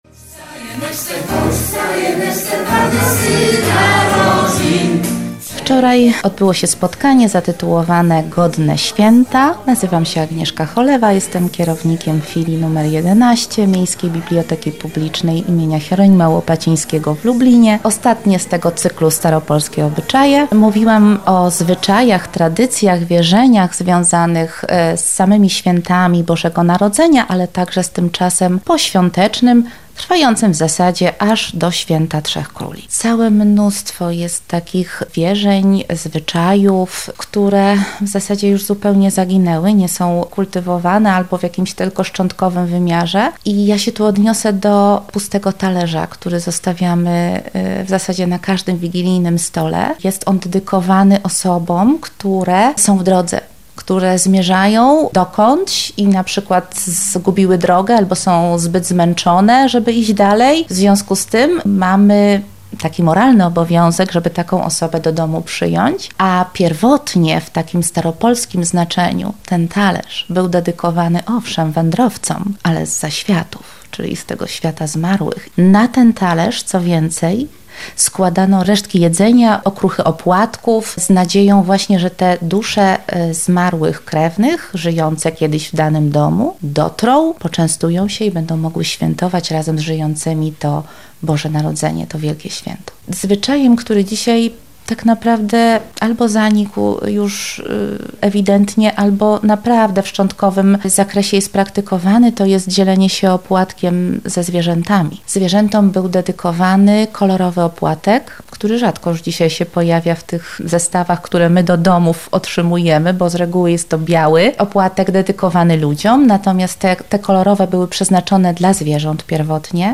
W filii nr 11 Miejskiej Biblioteki Publicznej w Lublinie odbyła się ostatnia prelekcja z cyklu "Staropolskie obyczaje" - tym razem pod hasłem "Godne Święta".